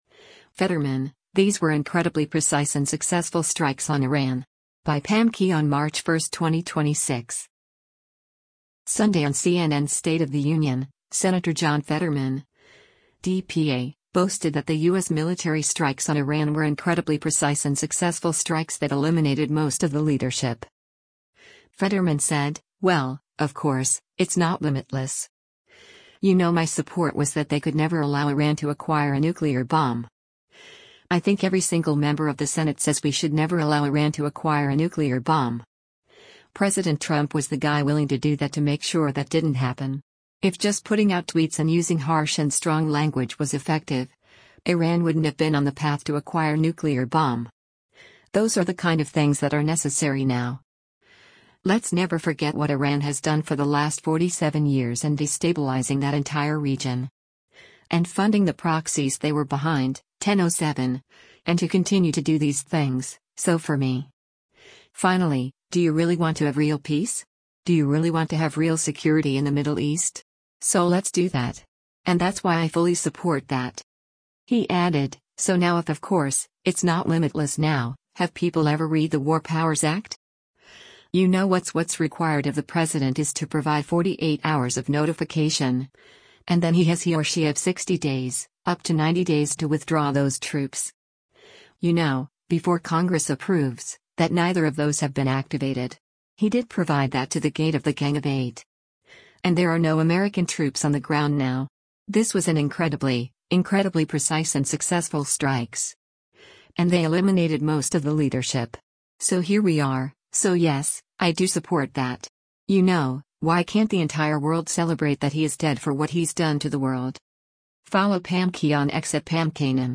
Sunday on CNN’s “State of the Union,” Sen. John Fetterman (D-PA) boasted that the U.S. military strikes on Iran were “incredibly precise and successful strikes” that “eliminated most of the leadership.”